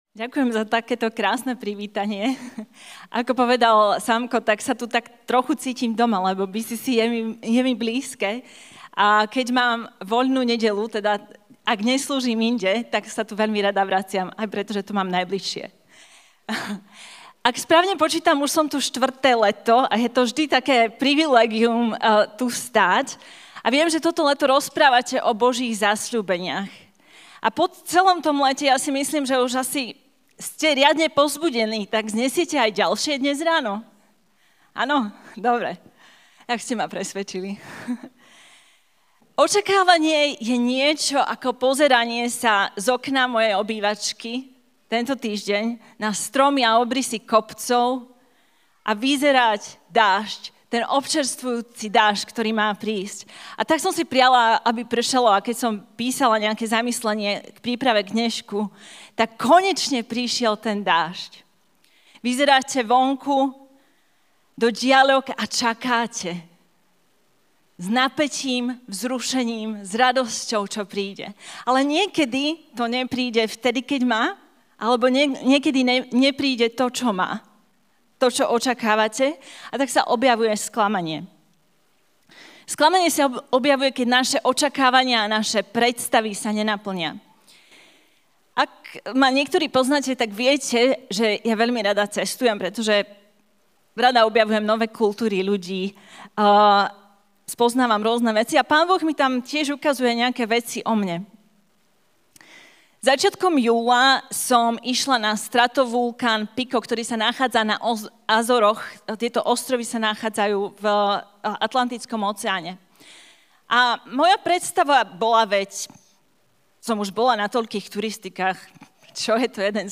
NE)očakávaná sila Kázeň týždňa Zo série kázní